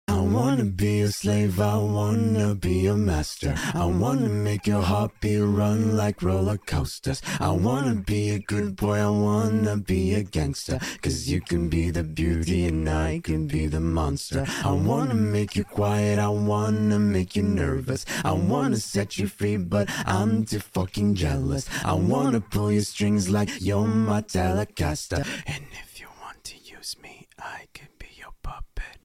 Valorant Singing Pt.147 Sound Effects Free Download